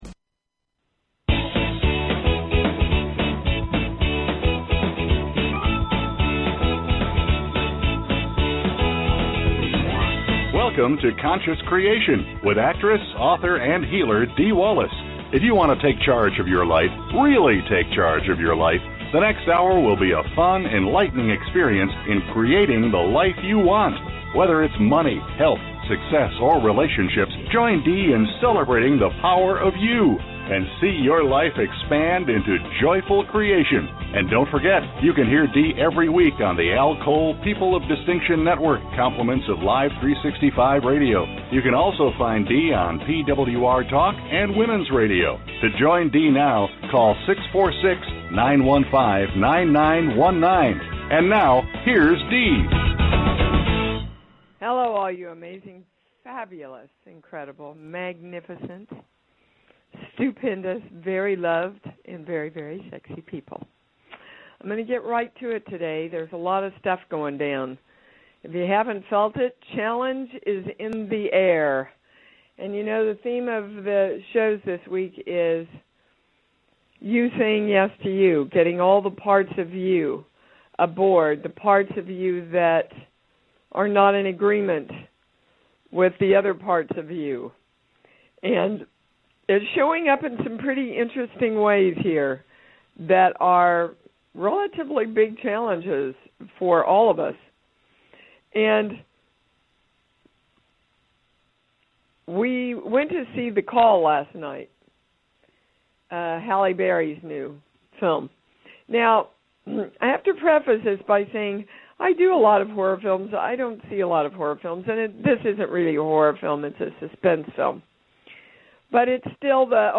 Talk Show Episode, Audio Podcast, Conscious Creation and with Dee Wallace on , show guests , about Core Truths,Balanced Life,Energy Shifts,Spirituality,Spiritual Archaeologist,Core Issues,Spiritual Memoir,Healing Words,Consciousness,Self Healing, categorized as Health & Lifestyle,Alternative Health,Energy Healing,Kids & Family,Philosophy,Psychology,Self Help,Spiritual,Psychic & Intuitive